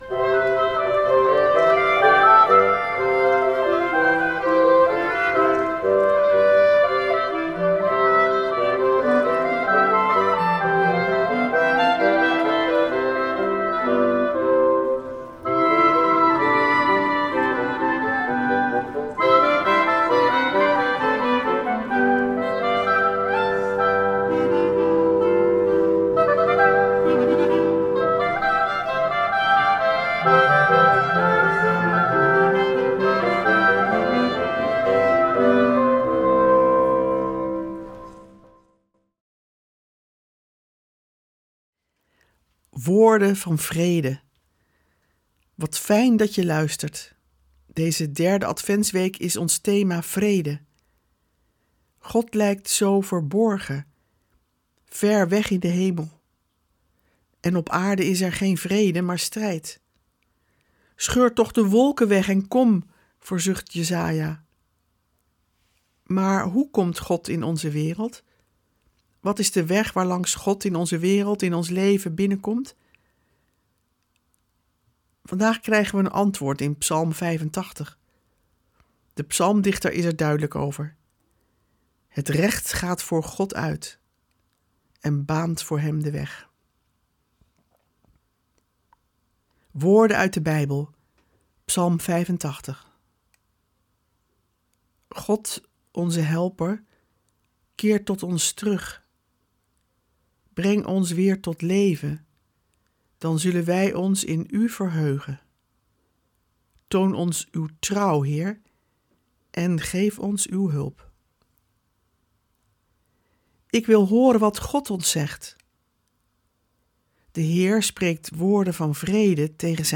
Met muziek en een actuele reflectie.